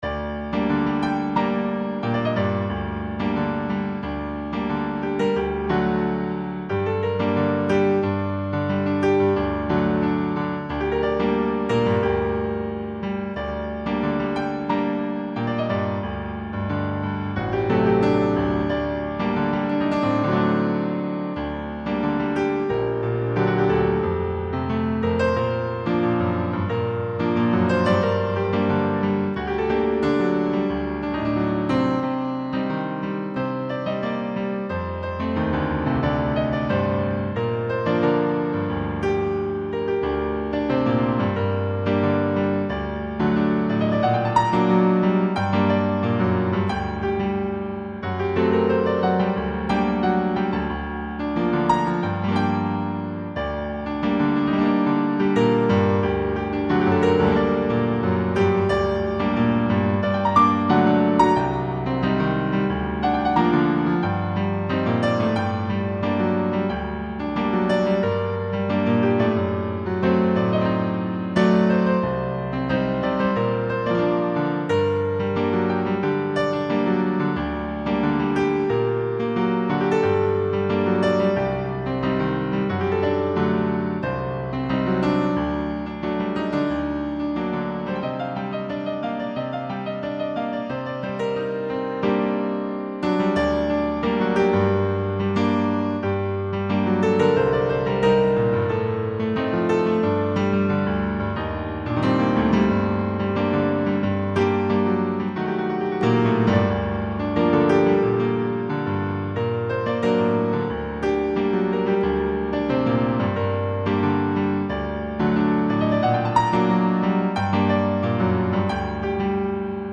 [21/7/2010]【原创】匈牙利探戈Op7.4 （钢琴）128kbs
【原创】匈牙利探戈Op7.4 （钢琴） 作者说明 匈牙利探戈作品7之4 我的音乐要民族性的！！！！！